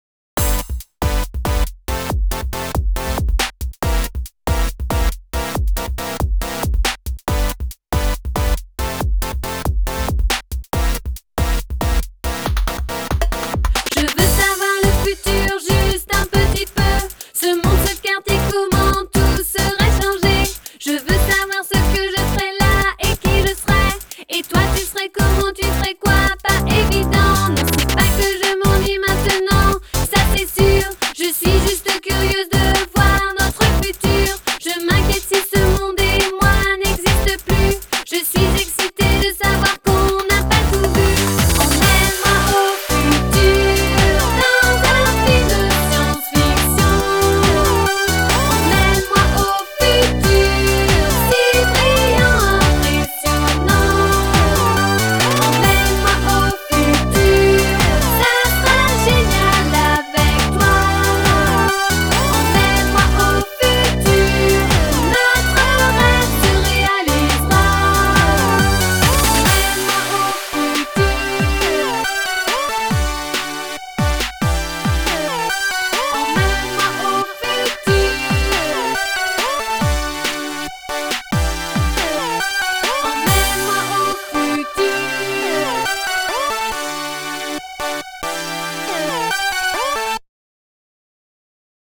BPM139
Audio QualityPerfect (High Quality)
It ended being shorter than I expected.